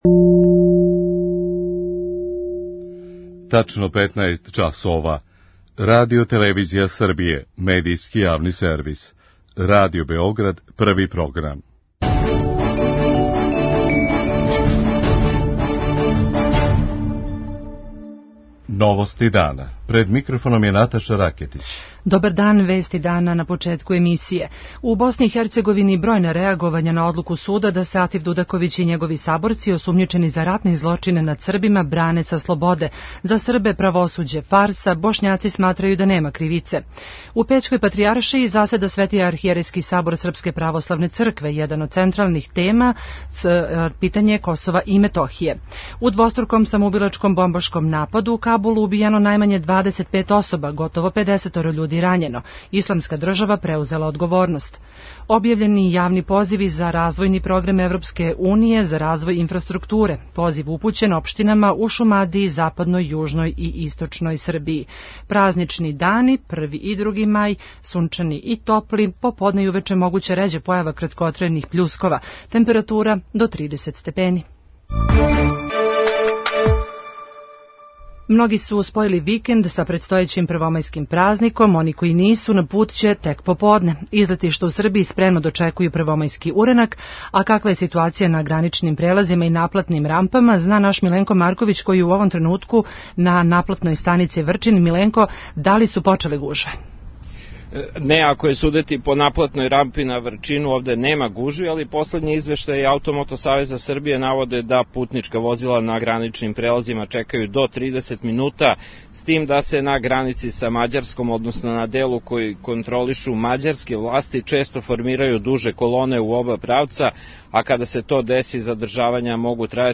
Новости дана